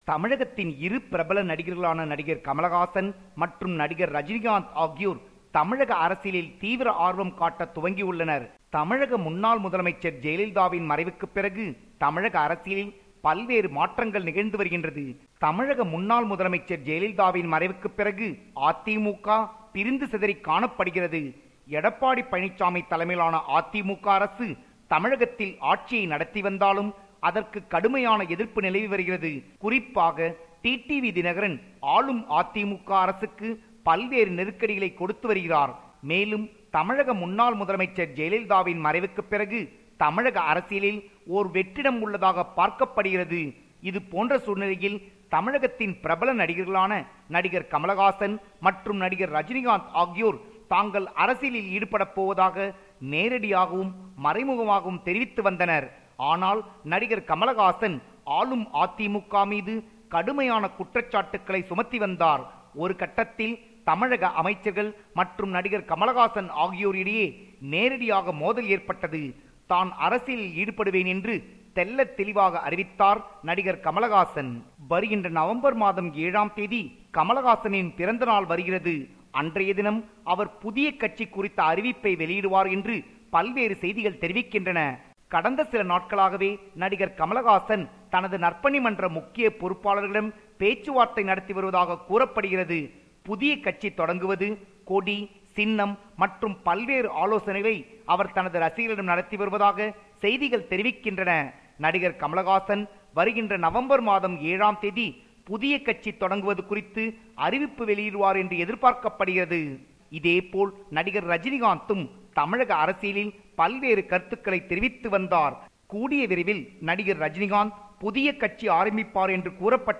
compiled a report focusing on major events in Tamil Nadu